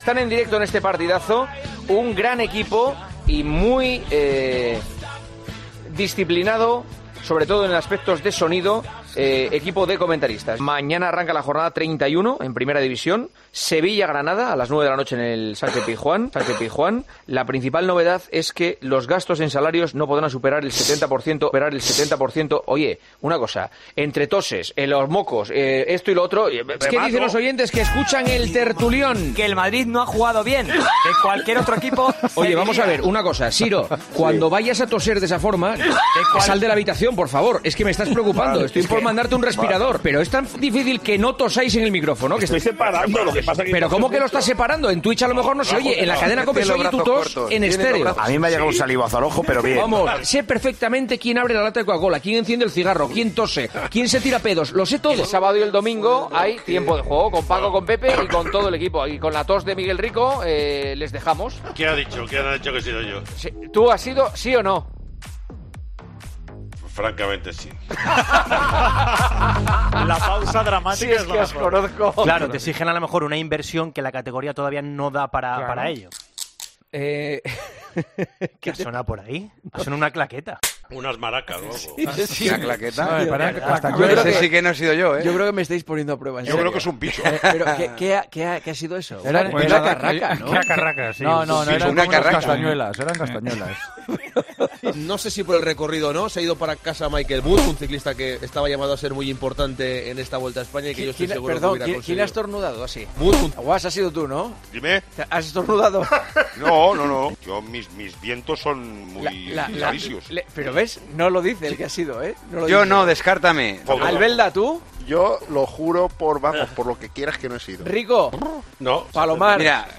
AUDIO: Recopilación de sonidos inexplicables, raros y que no deberían haberse producido dentro de El Partidazo de COPE.